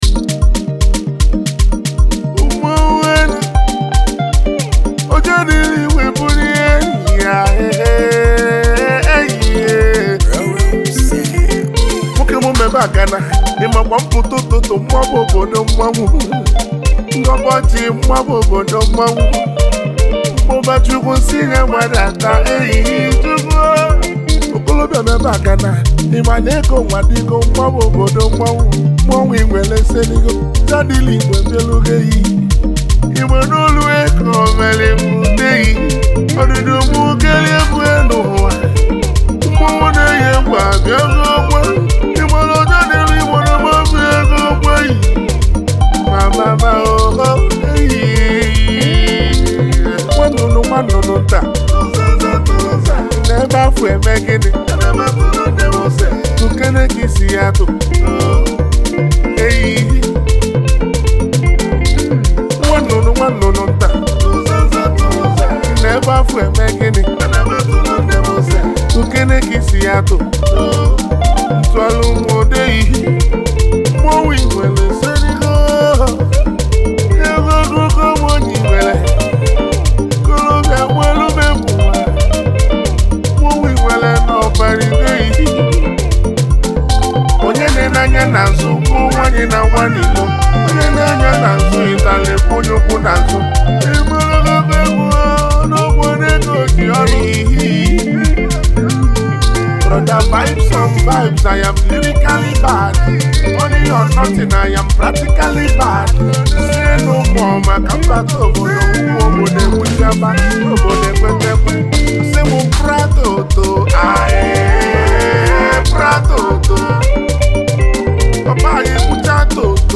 September 7, 2024 admin Highlife Music, Music 0